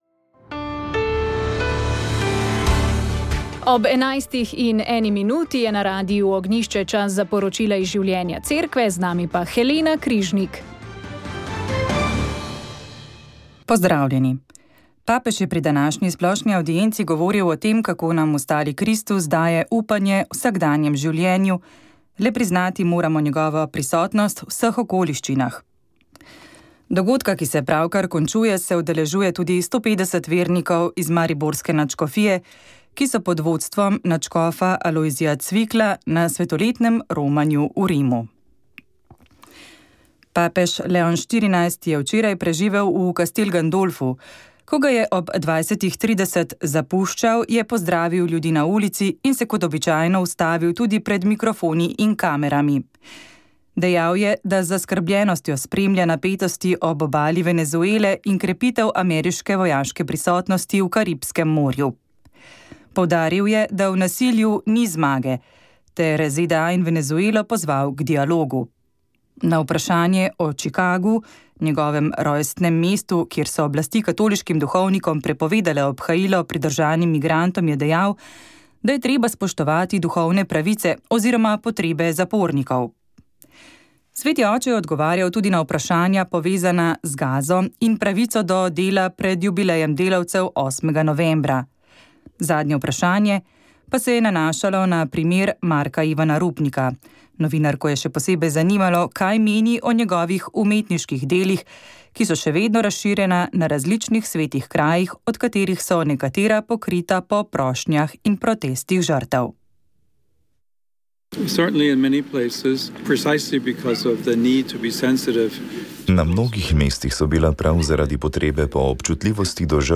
Najvišje dnevne temperature bodo od 16 do 20 °C Radio Ognjišče info novice Informativne oddaje VEČ ...